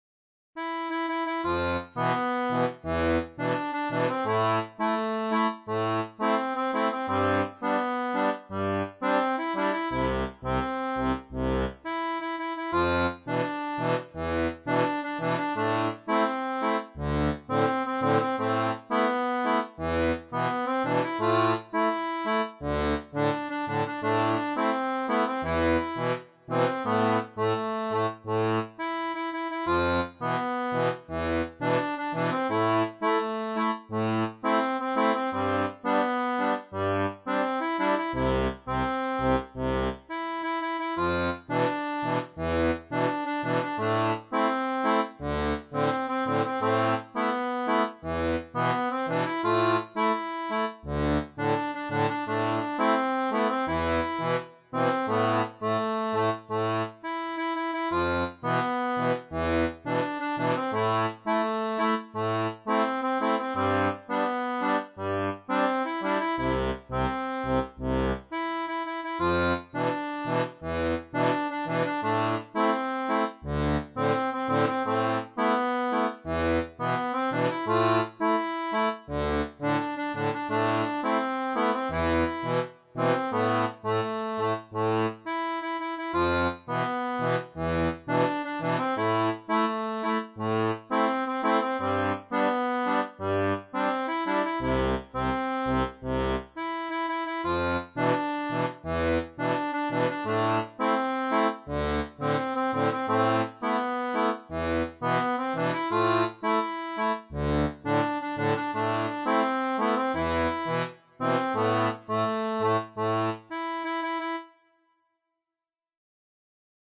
Type d'accordéon
Folk et Traditionnel